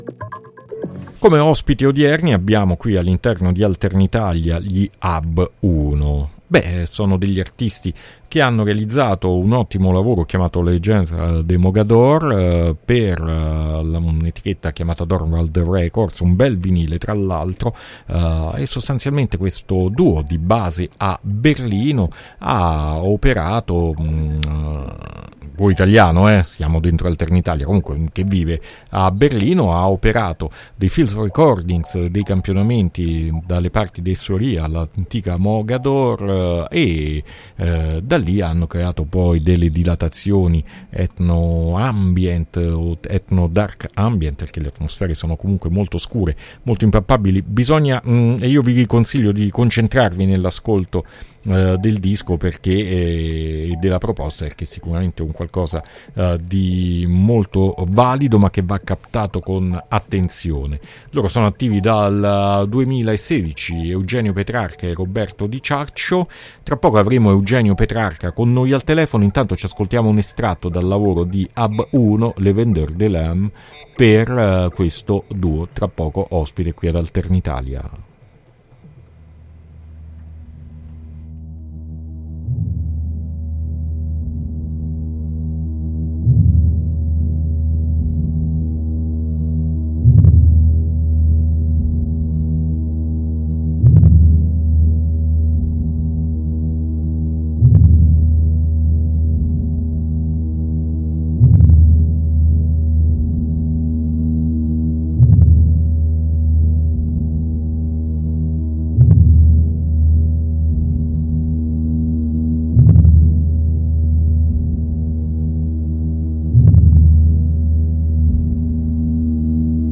INTERVISTA AB UNO ad ALTERNITALIA 9-9-2022